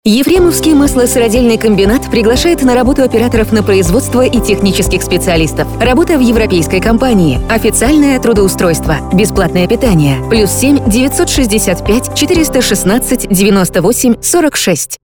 Началось размещение рекламы на радиостанции "Дорожное радио" Ефремовского маслосыродельного комбината в г. Ефремове.